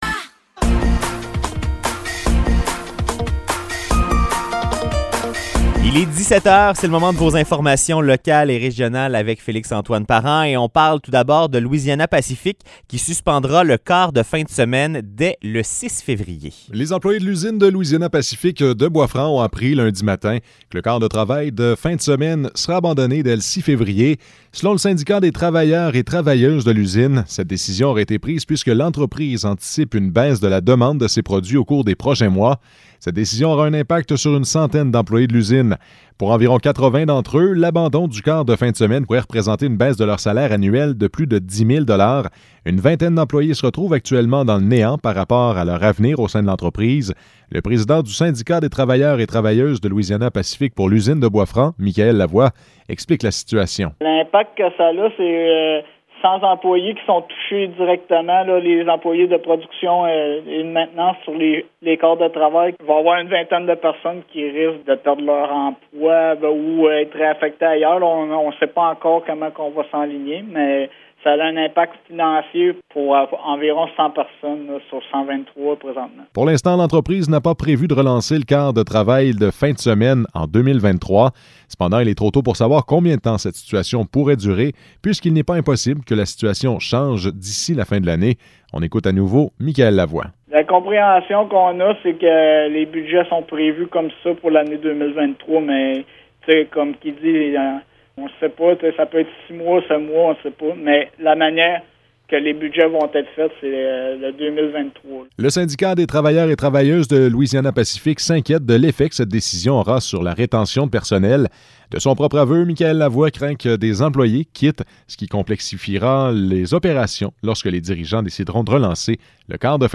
Nouvelles locales - 24 janvier 2023 - 17 h